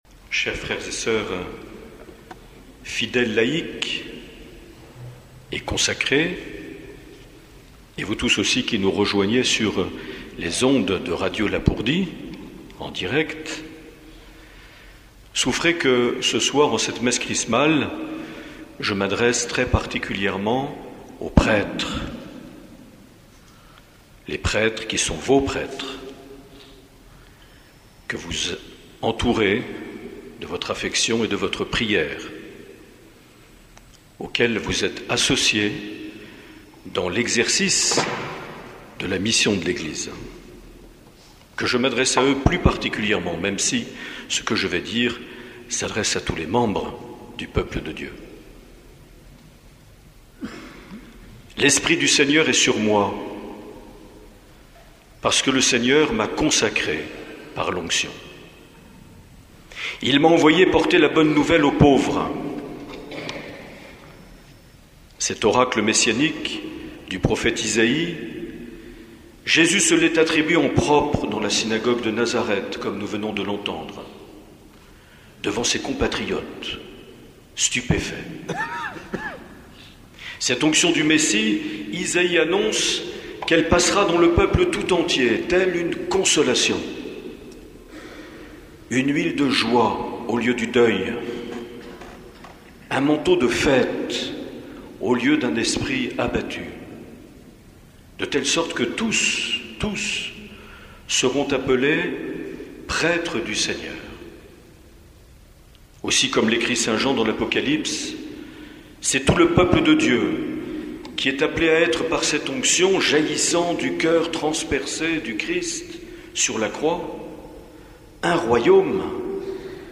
11 avril 2017 - Cathédrale d’Oloron - Messe Chrismale
Les Homélies
Une émission présentée par Monseigneur Marc Aillet